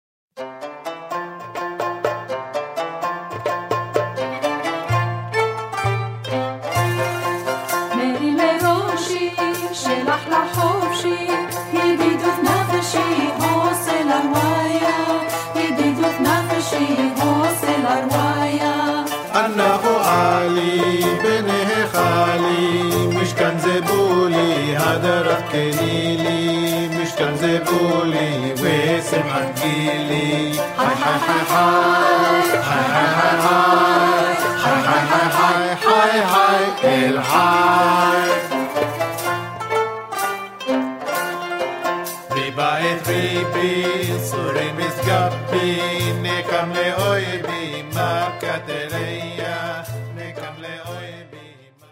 Iraqi Jewish and Arabic Song.